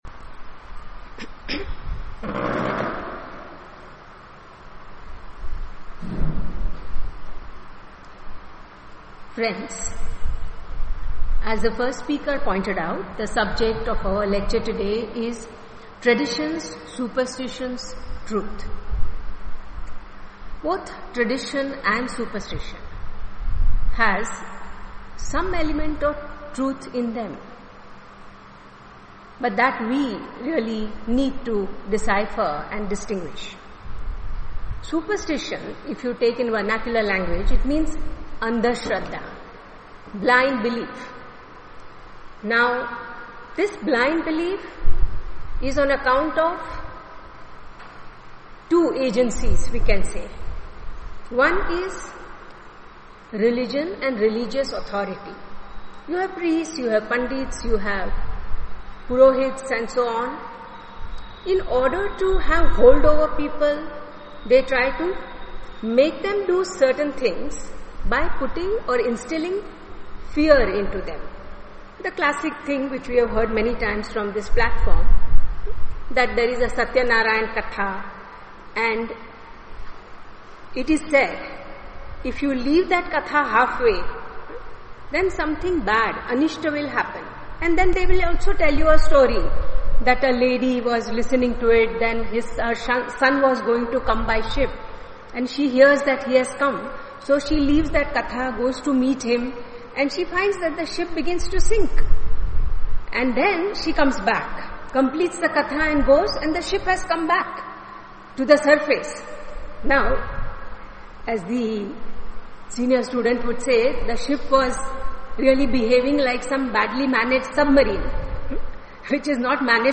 Uploaded Audio Lecture: Traditions Superstitions Truth
Dear Subscriber, An Audio Lecture entitled Traditions Superstitions Truth has been recorded and uploaded to our web site.